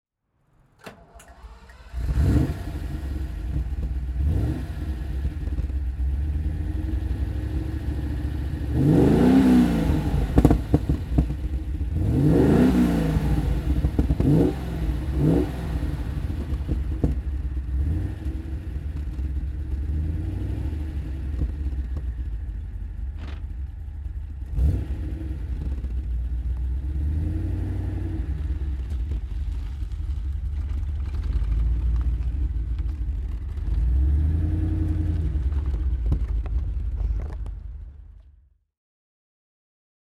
Maserati Khamsin (1977) - Starten und Leerlauf